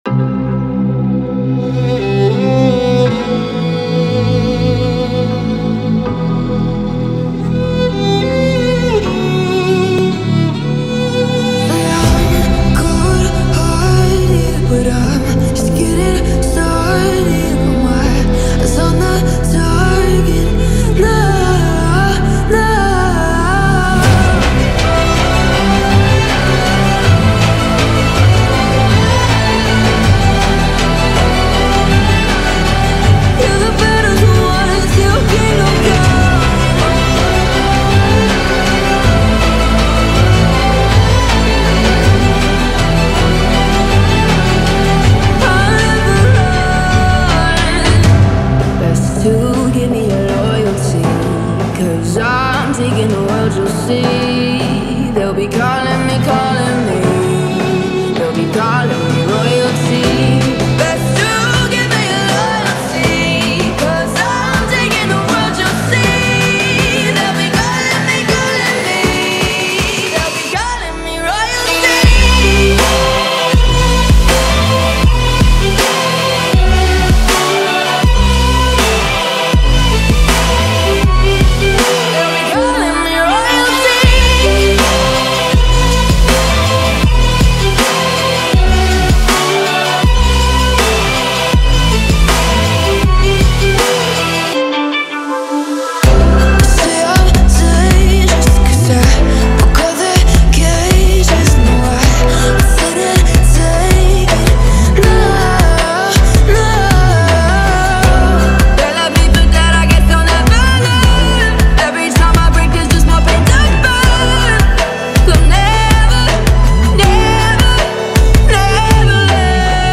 بسیار انگیزشی و همینطور حماسی هست
ویولن
ملودی بسیار حماسی هست